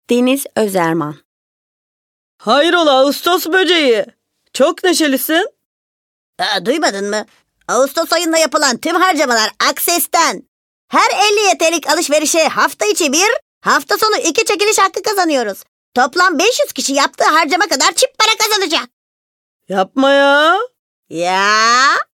KATEGORİ Kadın
ANİMASYON Canlı, Eğlenceli, Havalı, Karakter, Animasyon, Masalsı, Vokal, Promosyon, Dialekt, Sıcakkanlı, Film Sesi, Parlak, Genç, Dış Ses, Taklit, Dostane,